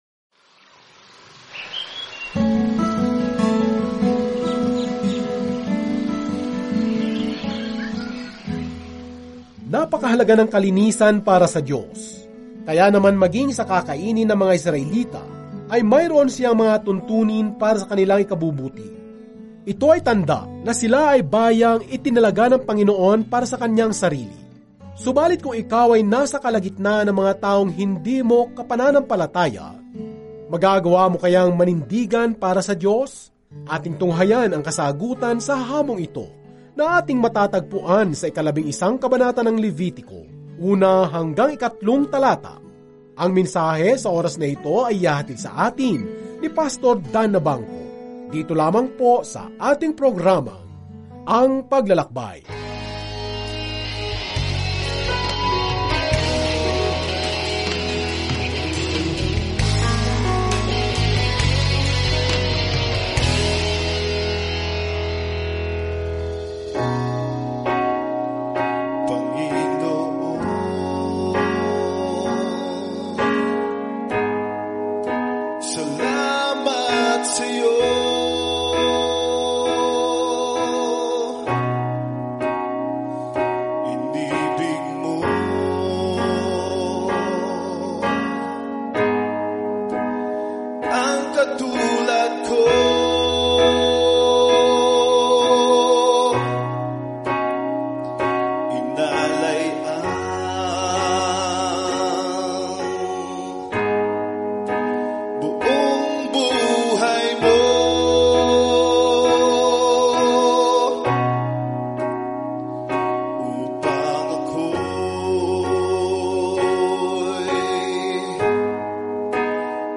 Sa pagsamba, paghahain, at pagpipitagan, sinasagot ng Levitico ang tanong na iyan para sa sinaunang Israel. Araw-araw na paglalakbay sa Leviticus habang nakikinig ka sa audio study at nagbabasa ng mga piling talata mula sa salita ng Diyos.